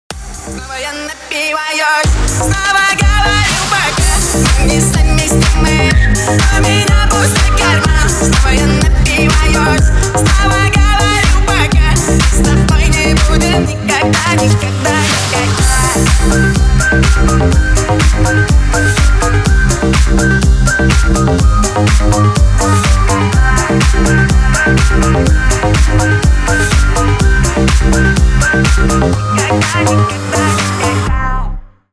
Ремикс # Поп Музыка # Рэп и Хип Хоп